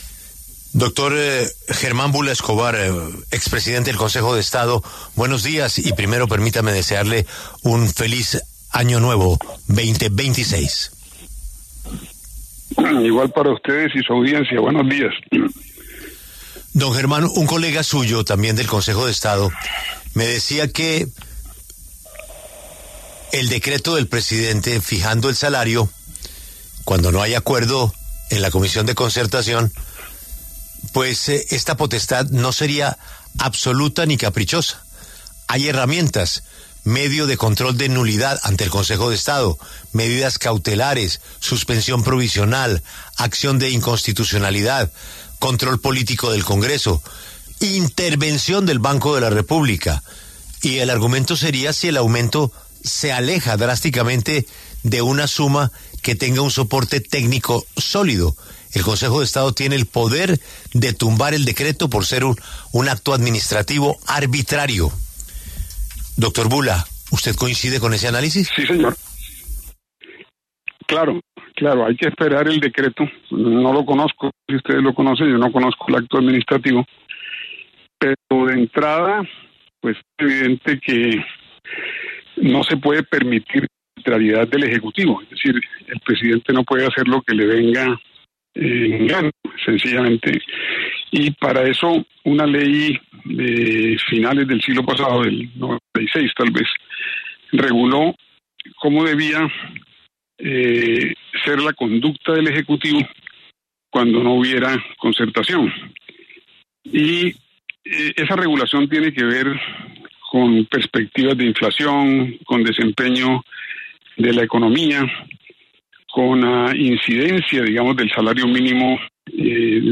En entrevista con La W, el expresidente del Consejo de Estado, Germán Bula Escobar, se refirió al decreto del aumento del salario mínimo en 23.7%.